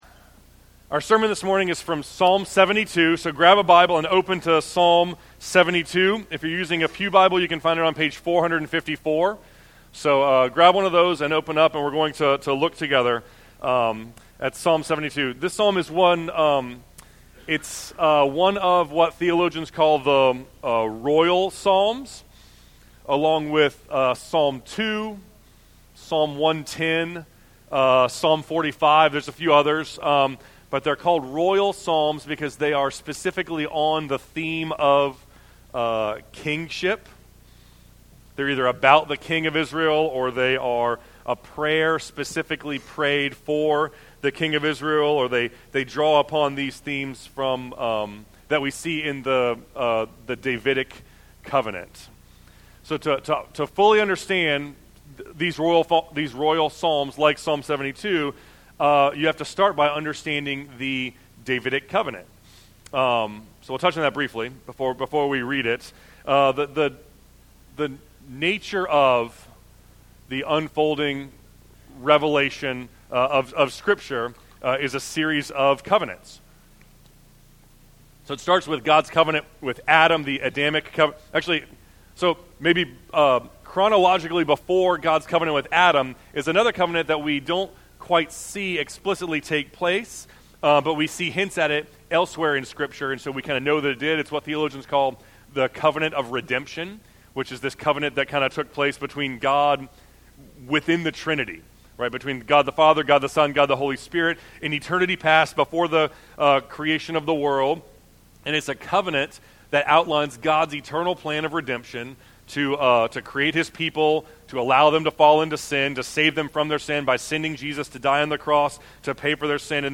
Sermons | James River Community Church